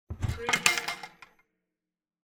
Cabinet door open sound effect .wav #6
Description: The sound of a wooden cabinet door being pulled open (and some loose items attached to the door rattling)
Properties: 48.000 kHz 16-bit Stereo
cabinet-door-open-preview-6.mp3